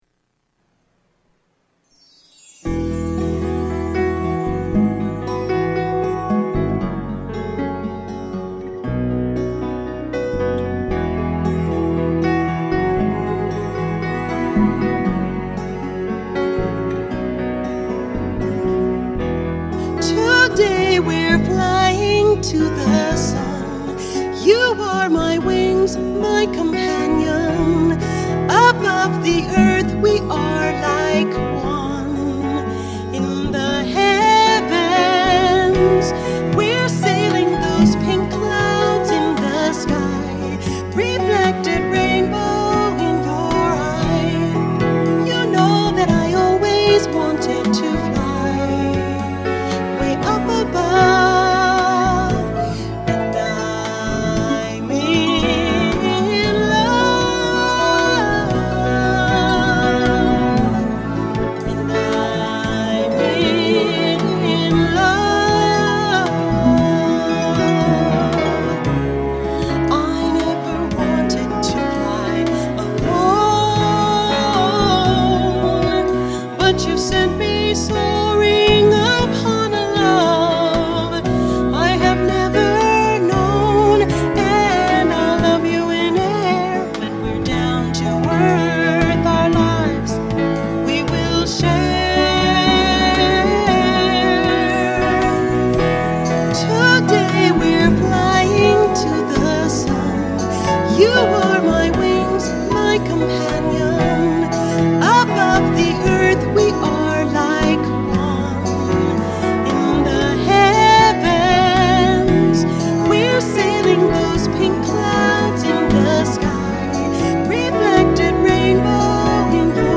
(lower)